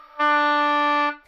双簧管单音（吹得不好） " 双簧管 D4 丰富性不好的簧片
描述：在巴塞罗那Universitat Pompeu Fabra音乐技术集团的goodsounds.org项目的背景下录制。
Tag: 好声音 D4 单注 多重采样 纽曼-U87 双簧管